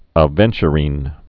(ə-vĕnchə-rēn, -rĭn) also a·ven·tu·rin (-rĭn)